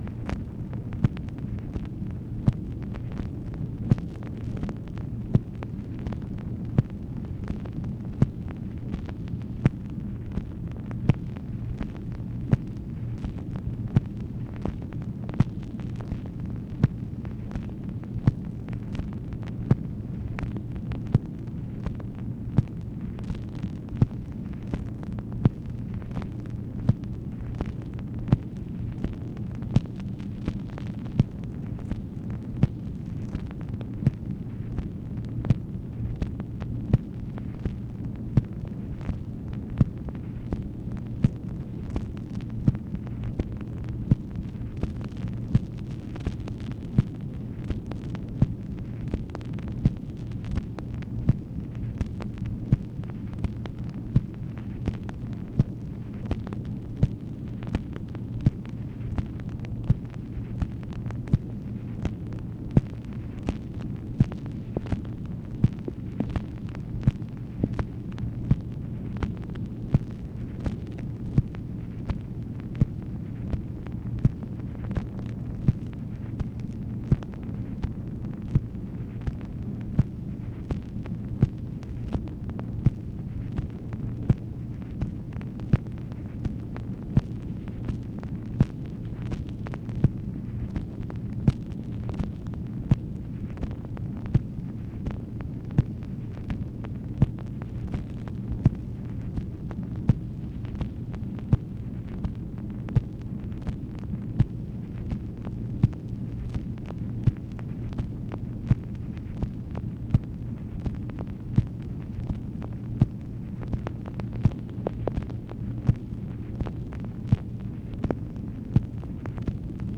MACHINE NOISE, March 4, 1966
Secret White House Tapes | Lyndon B. Johnson Presidency